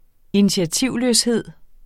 Udtale [ -ˌløːsˌheðˀ ]